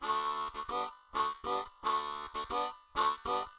口琴练习剪辑 " 口琴节奏 01 (可循环)
描述：我在Marine Band口琴上演奏了节奏裂痕。
Tag: 口琴 节奏 重点 G